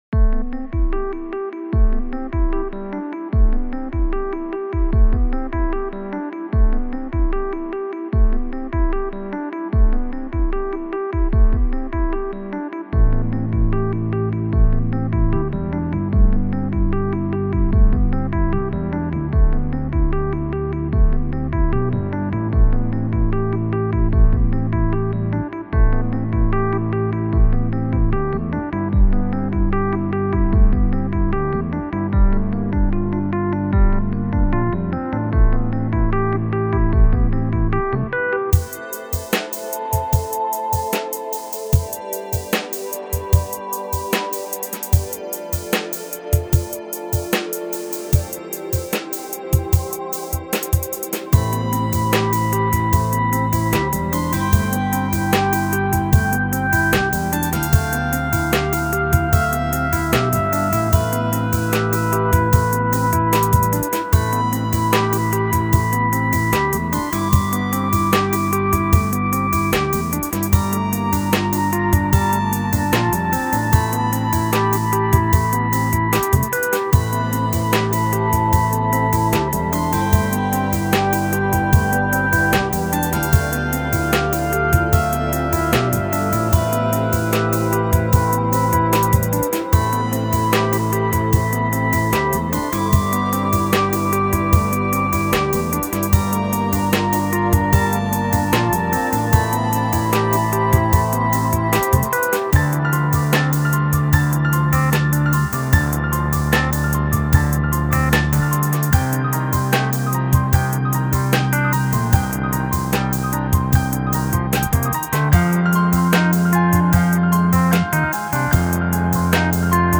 SLOW-TEMPO CHILL-OUT MOODY DREAMY ELECTRONIC PIANO DRUMS